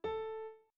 01_院长房间_钢琴_07.wav